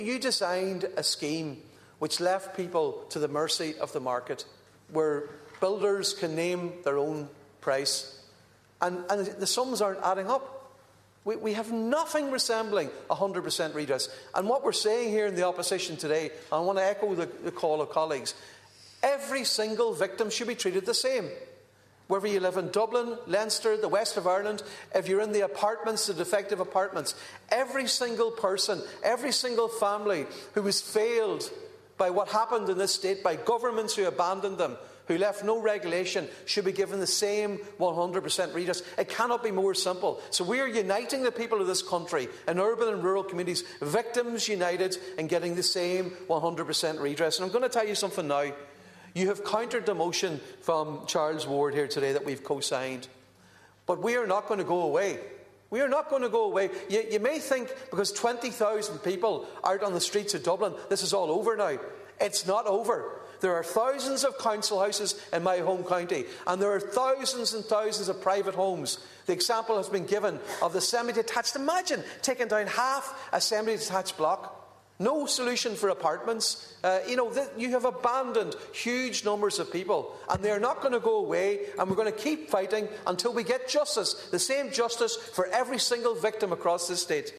Donegal Deputy Padraig MacLochlainn was one of the opposition members who spoke on the motion.